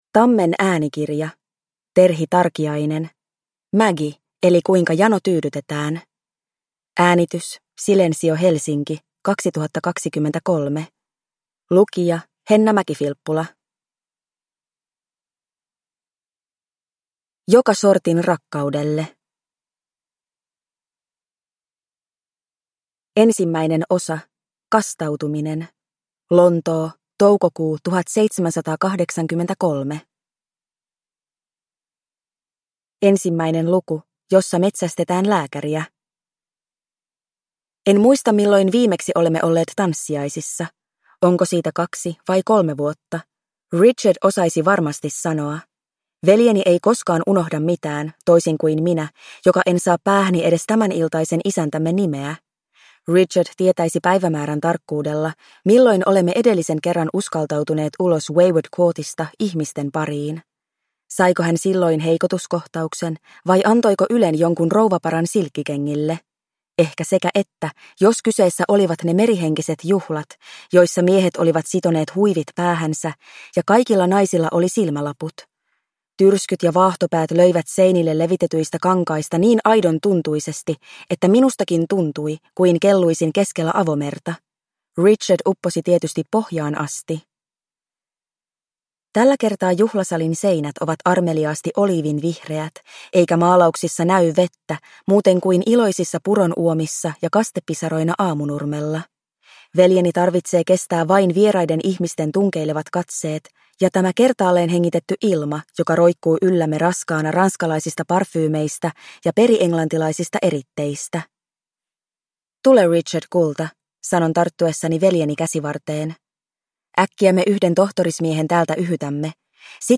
Maggie – Ljudbok – Laddas ner